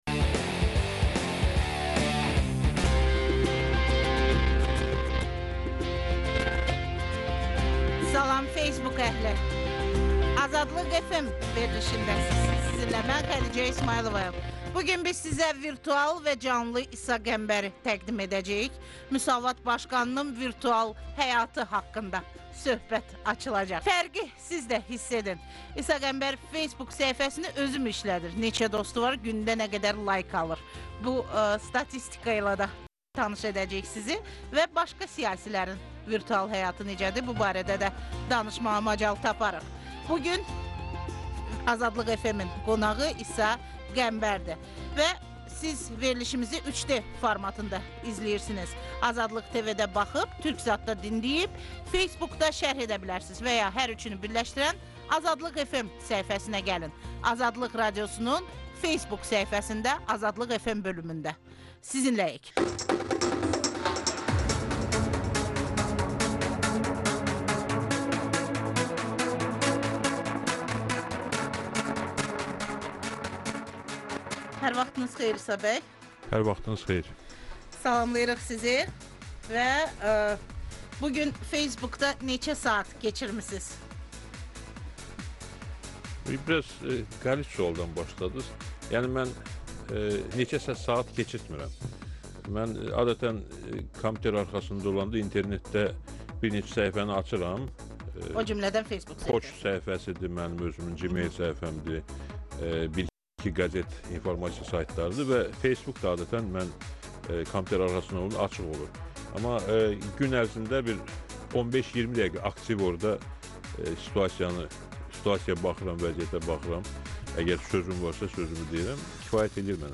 İsa Qəmbər AzadlıqFM proqramında facebook əhlinin suallarına cavab verir